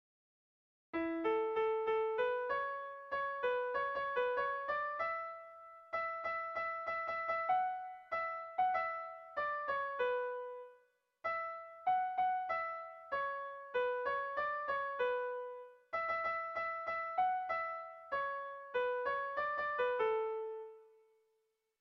Erlijiozkoa
Neurrian motz dabil erdialde horretan.
ABD1D2